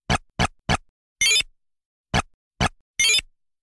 While we’re considering the game design of this thread, here’s an idea that might be fun: I wonder if these completely isolated sound effects are iconic enough to identify without any other context?